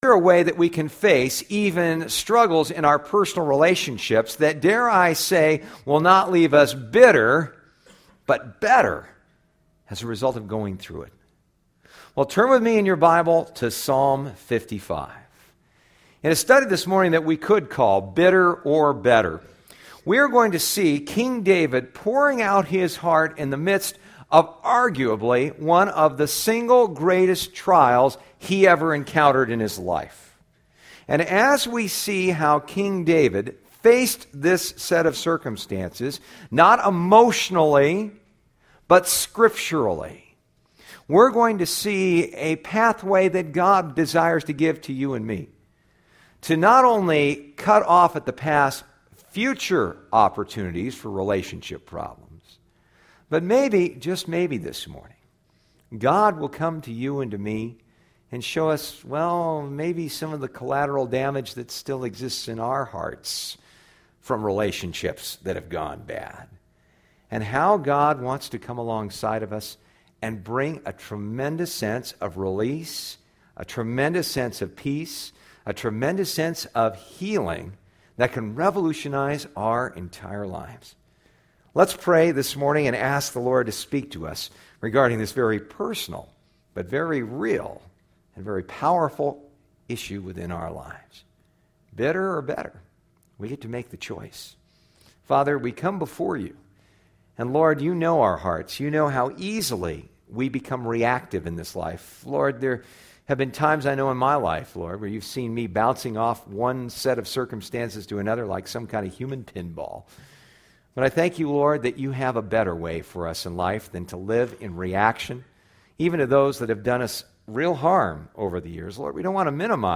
Psalm 55 Service Type: Sunday Morning « Psalm 55